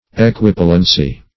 Search Result for " equipollency" : The Collaborative International Dictionary of English v.0.48: Equipollence \E`qui*pol"lence\, Equipollency \E`qui*pol"len*cy\, n. [Cf. F. ['e]quipollence.